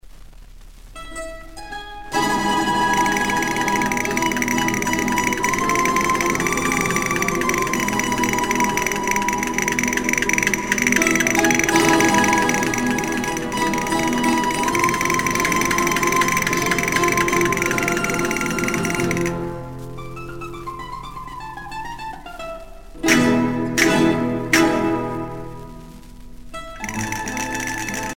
danse : flamenco
Pièce musicale éditée